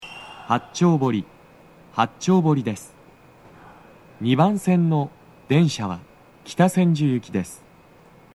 スピーカー種類 BOSE天井型
2番線は足元注意喚起放送の付帯は無く、北千住行きのフルは比較的鳴りやすいです。
到着放送 【男声